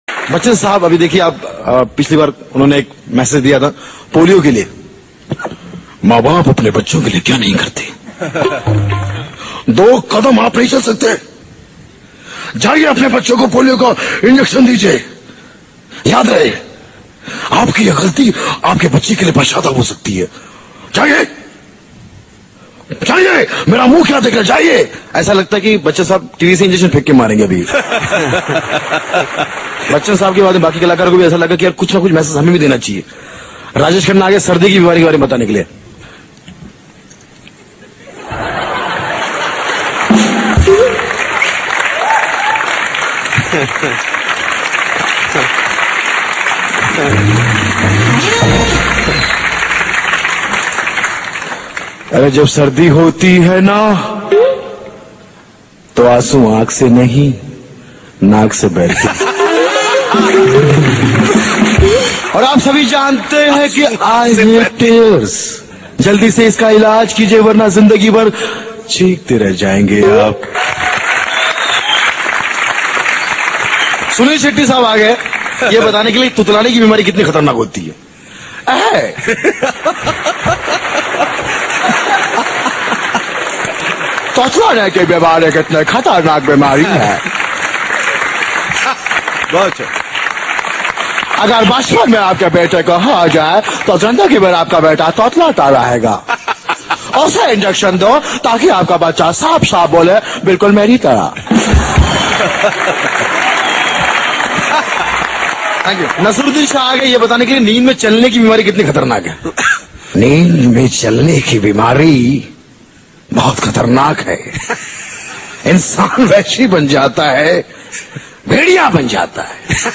Funny Mimicry Voice